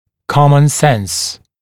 [‘kɔmən sens][‘комэн сэнс]здравый смысл